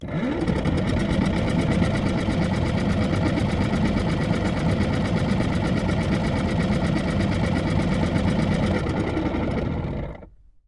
沃尔沃加热器 " 沃尔沃马达 3
描述：一辆老沃尔沃旅行车的加热器旋转起来，运行，然后停止。 它非常明显，而且，坏了。 2010年9月用Zoom H4录制的。 没有添加任何处理。
Tag: 加热器 电动机 旋转 沃尔沃 抱怨